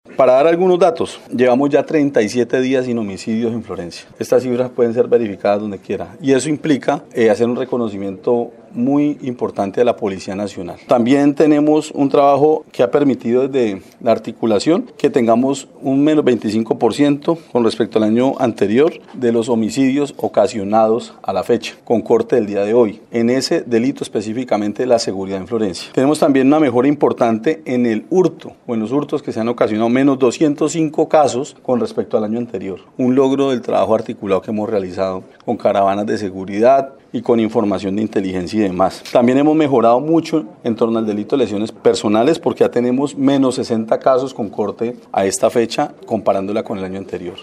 El alcalde de Florencia, Marlon Monsalve Ascanio, también destacó los resultados en la lucha contra el narcotráfico que adelanta la fuerza pública en esta parte, donde en las últimas horas, fueron incautados 800 kilos de marihuana, que pretendían ingresar a la ciudad.
Monsalve Ascanio, agregó que continuará trabajando en el acercamiento y dialogo con las comunidades de una manera más directa, casi que puerta a puerta, para obtener resultados más directos y contundentes en materia de seguridad.